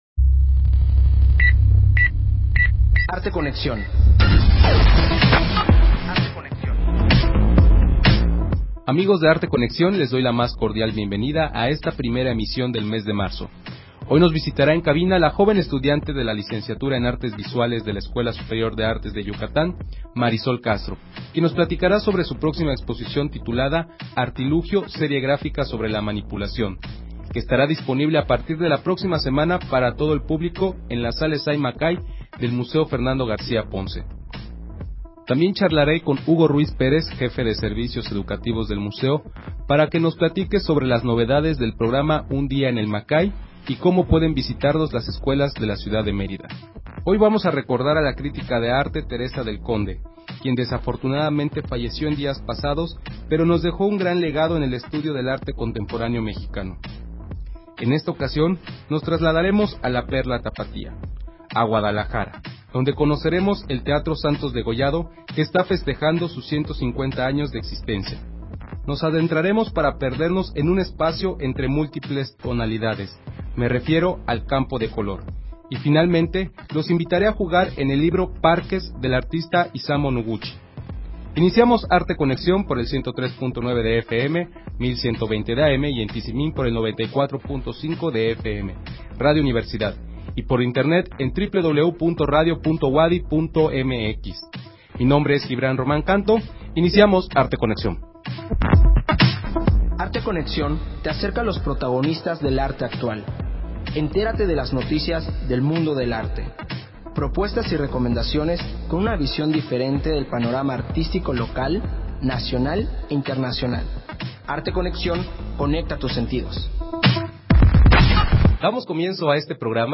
Emisión de Arte Conexión transmitida el 2 de marzo del 2017.